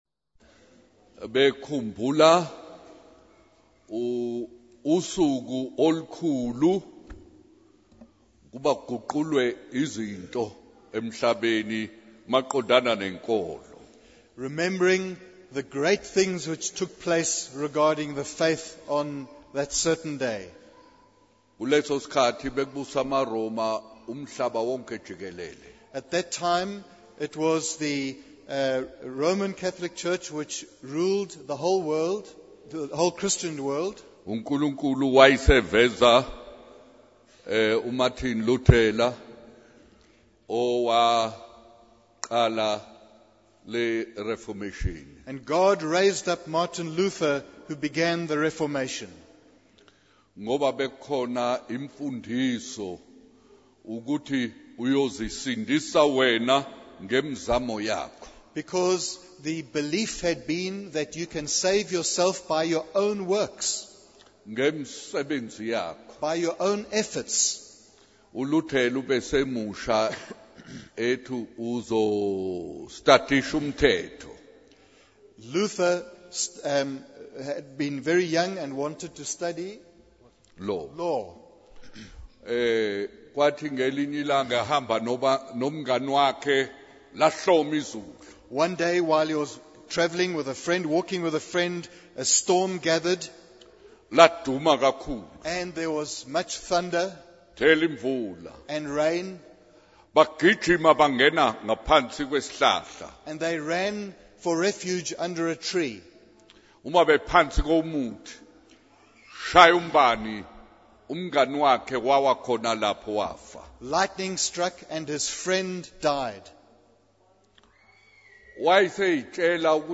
In this sermon, the preacher discusses the story of David and how he tried to cover up his sins until God exposed him. The preacher then shares a story about a little girl who had a powerful encounter with God when she saw a great eye looking at her.